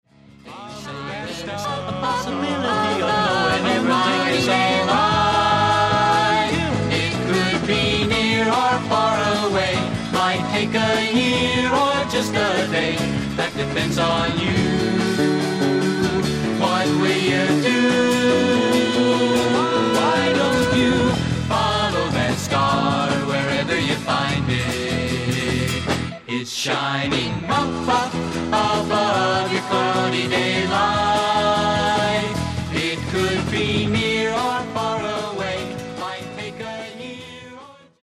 女性一人を含むテキサス出身の4人組